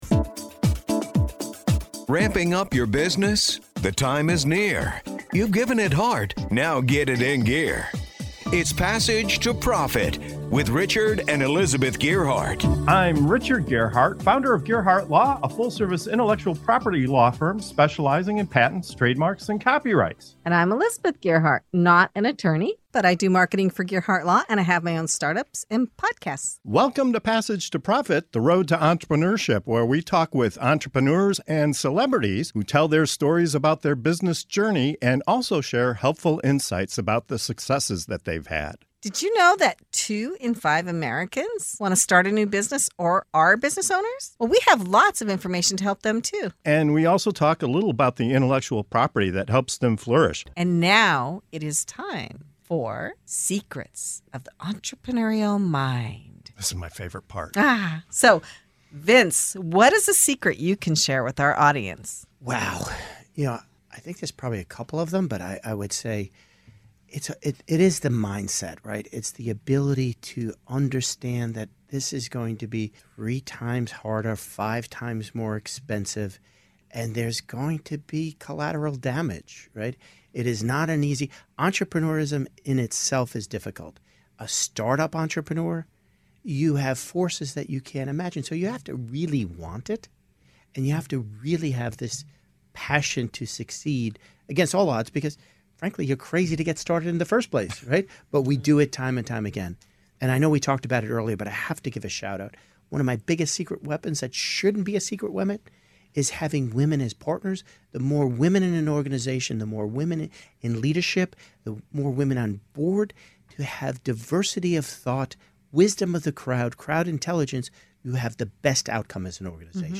From harnessing resilience in the face of rejection to transforming self-doubt into self-belief, each guest shares the unique strategies that drive them forward. Discover why having women in leadership, embracing the “I Am” mindset, and learning to love the grind (and the fun) are game-changers in the world of entrepreneurship.